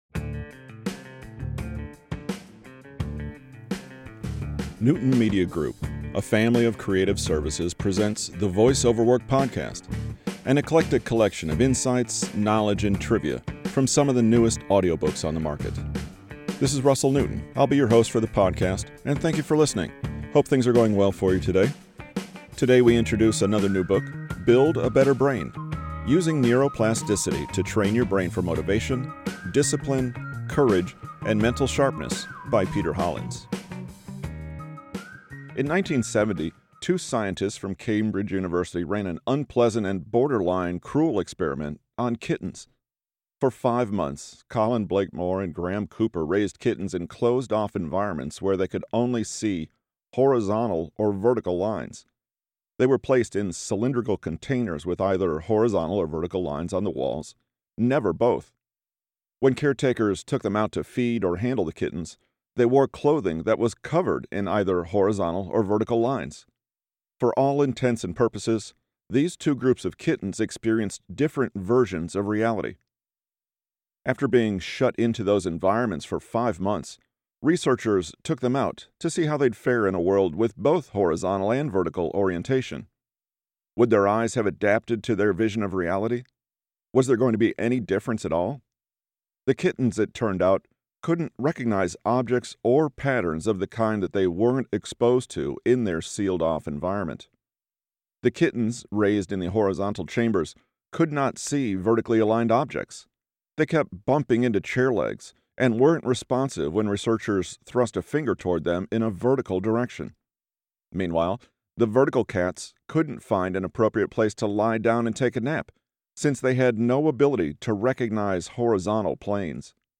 Don’t Try This at Home – To Your Cat – Voice over Work